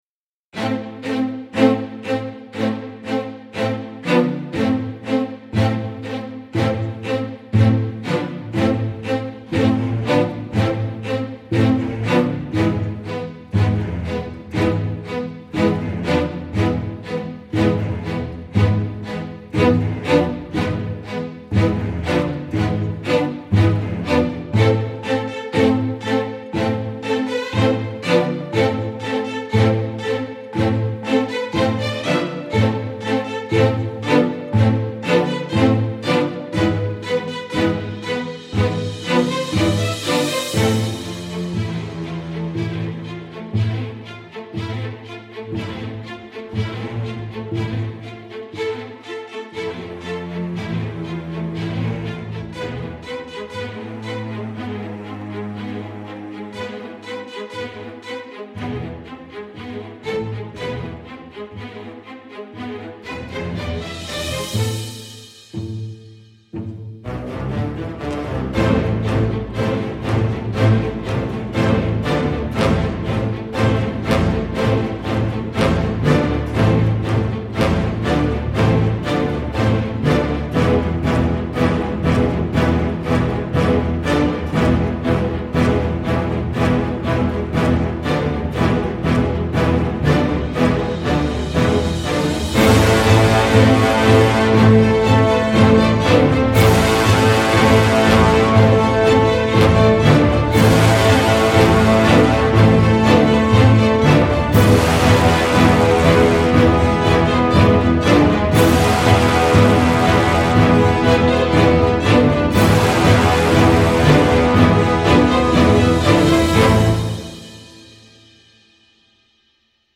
Allergiques au déluge nostalgique, fuyez !